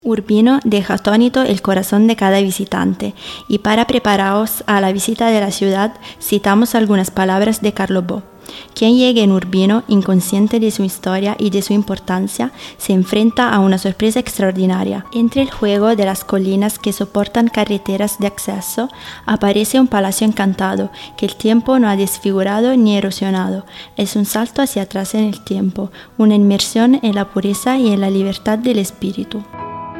AUDIOGUIDA E SISTEMA MULTILINGUE